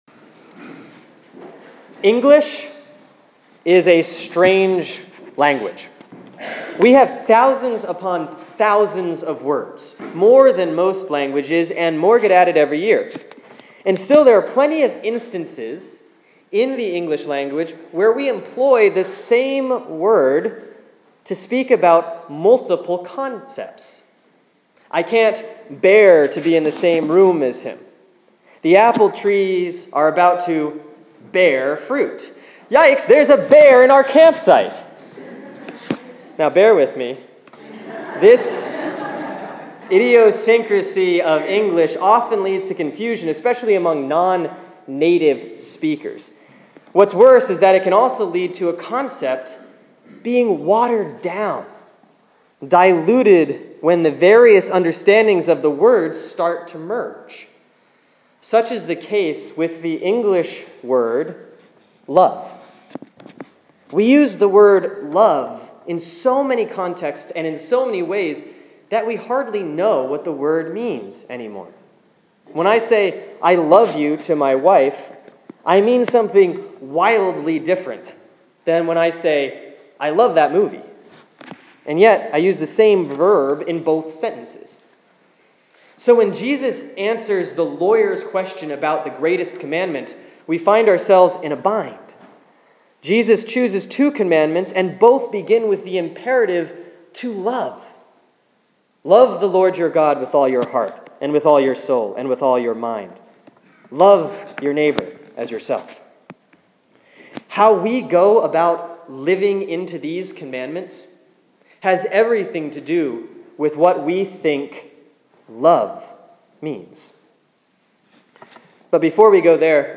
Sermon for Sunday, October 26, 2014 || Proper 25A || Matthew 22:34-46